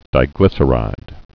(dī-glĭsə-rīd)